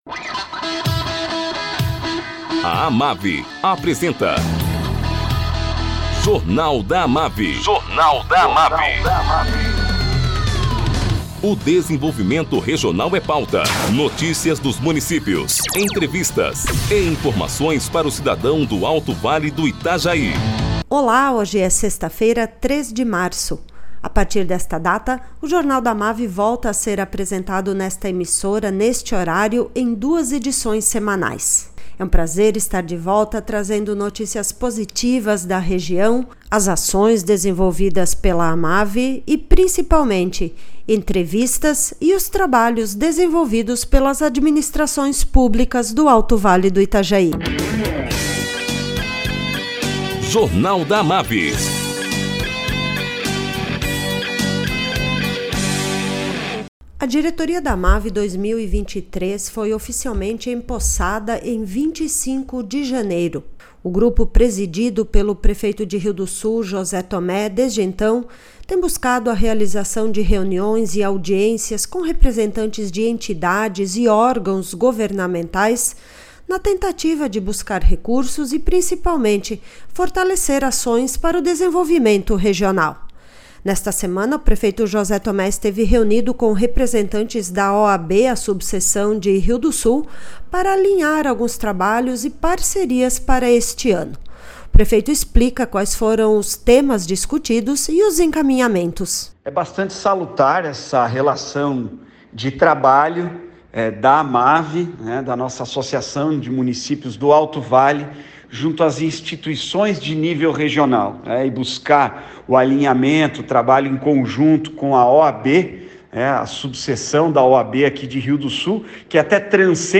Presidente da AMAVI, prefeito José Thomé,fala sobre reunião realizada nesta semana com representantes da OAB.